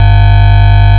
Zero order hold signal from D/A